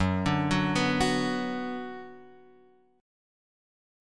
F#sus4#5 chord {2 5 4 4 x 2} chord